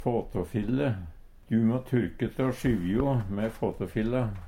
fåtåfille - Numedalsmål (en-US)